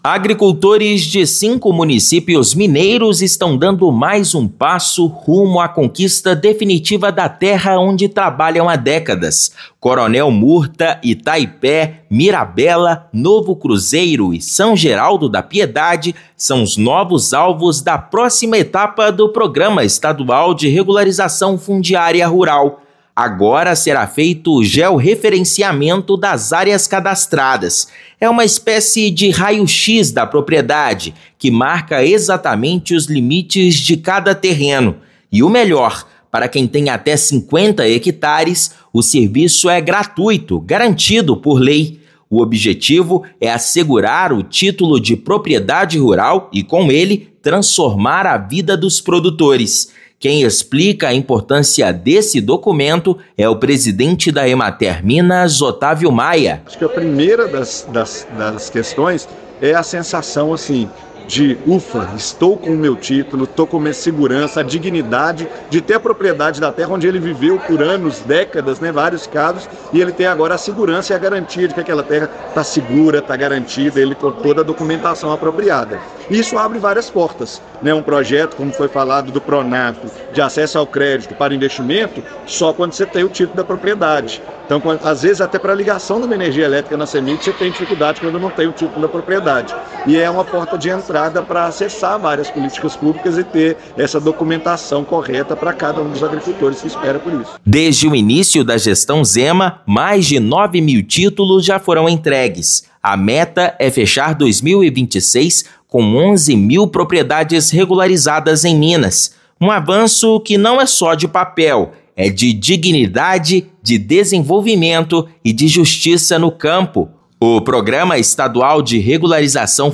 Agricultores de mais cinco cidades mineiras estão prestes a garantir o direito sobre as áreas rurais onde vivem e trabalham. Ouça matéria de rádio.